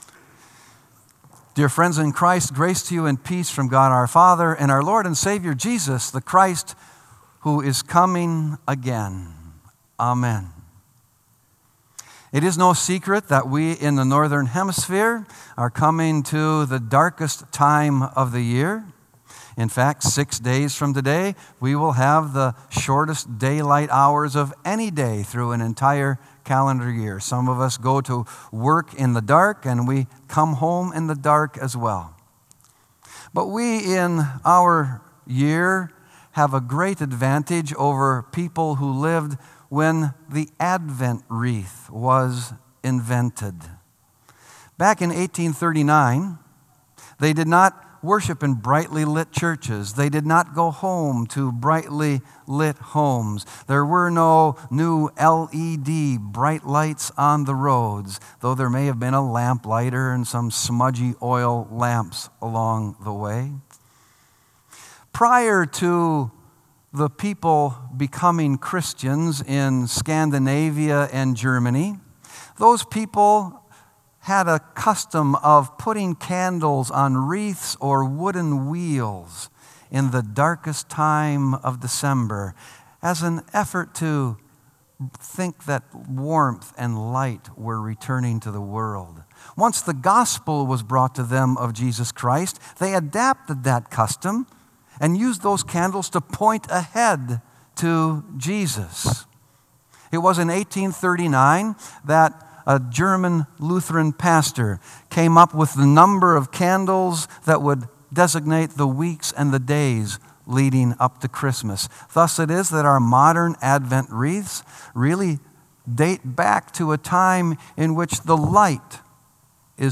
Sermon “A Light To the Nations”